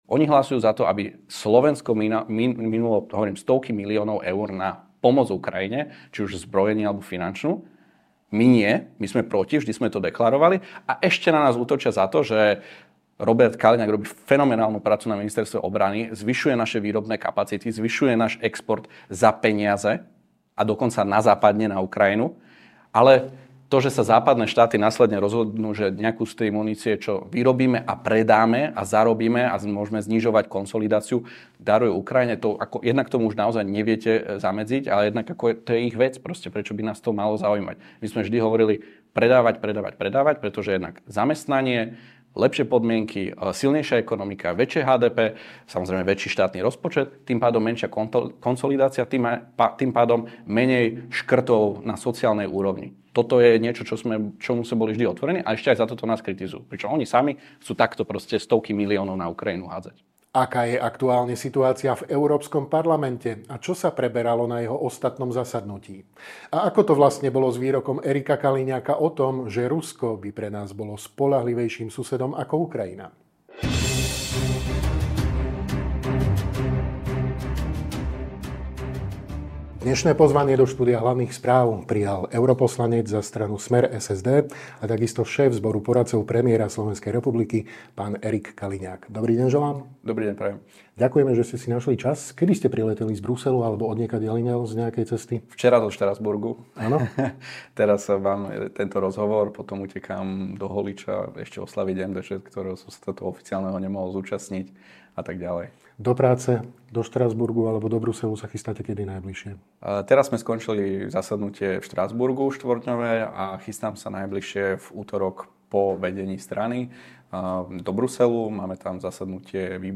Viac vo videorozhovore.